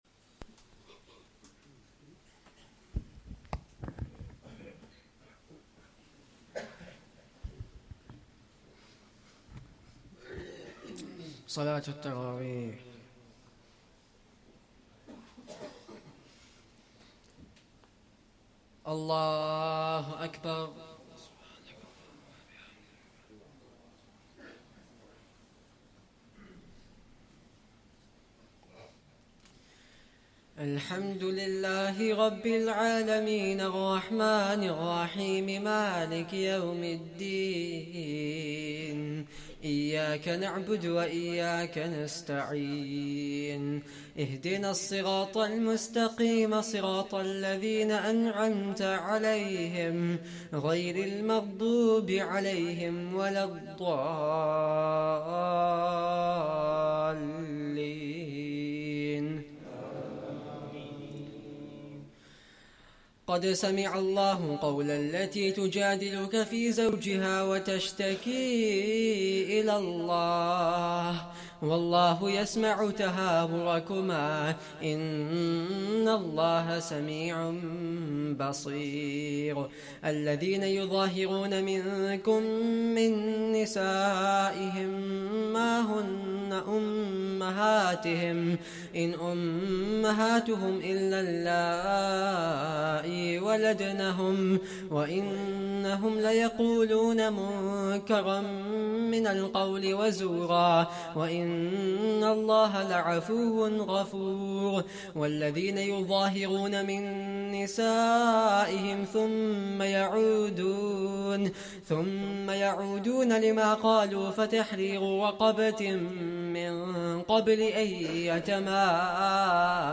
2nd Tarawih prayer - 26th Ramadan 2024